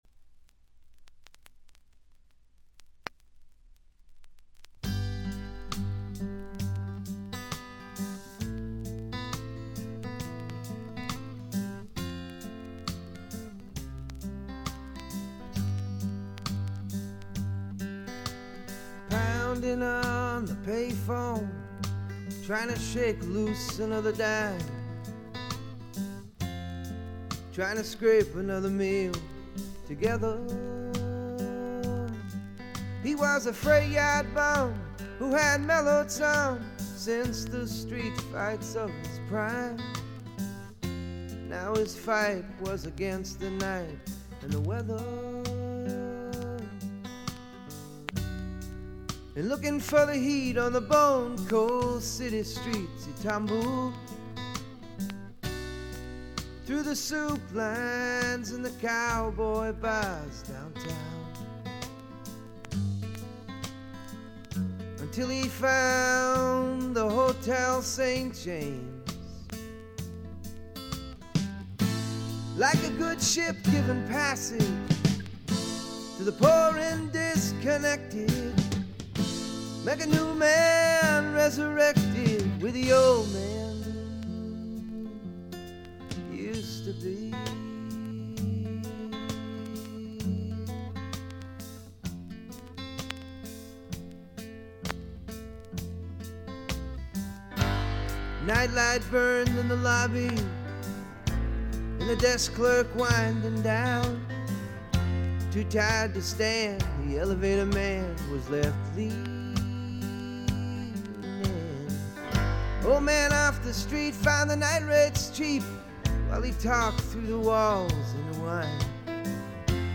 主に静音部で軽微なチリプチ（A3序盤では目立ちます）。
ボブ・ディランのフォロワー的な味わい深い渋い歌声はそのままに、むしろ純度がより上がった感があります。
試聴曲は現品からの取り込み音源です。